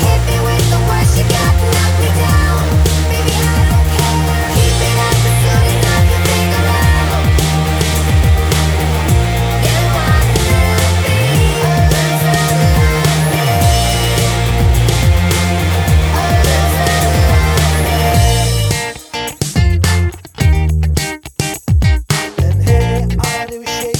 no Backing Vocals Soundtracks 3:19 Buy £1.50